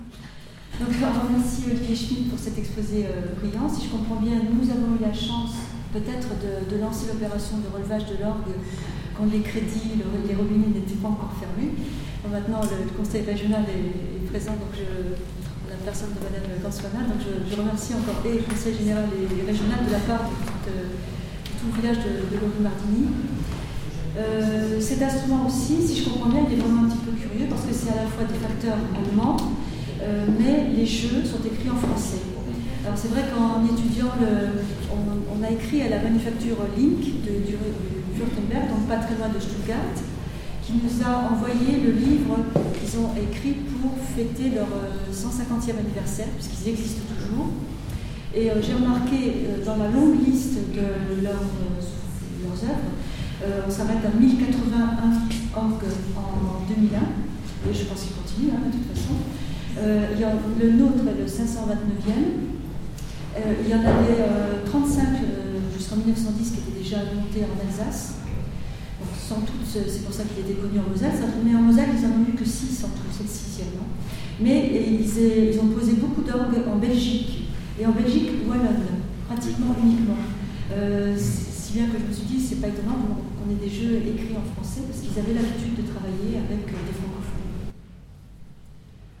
Extraits de la conférence